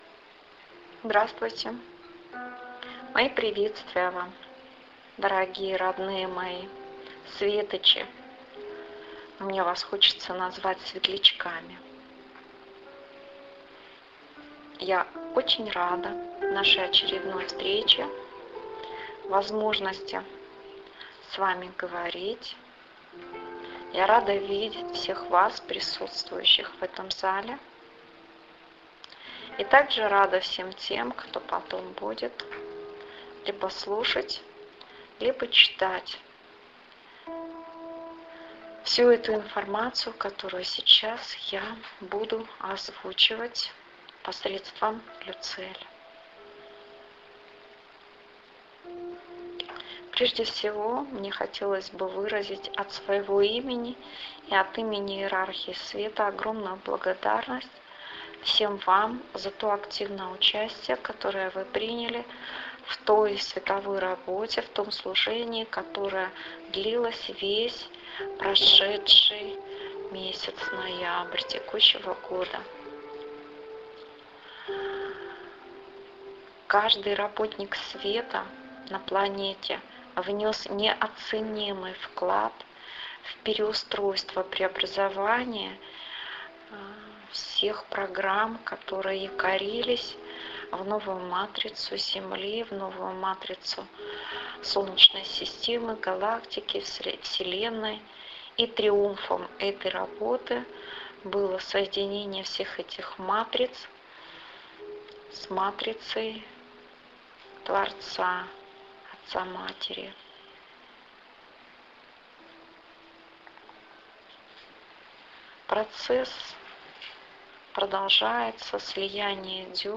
Предлагаю вам очередное Послание Гайи, которое было озвучено на занятии, группы «Рэйки-Возрождение», 2 декабря. В этом Послании Гайя разворачивает истину состояния открытого Сердца и отношения к Новым Детям Земли.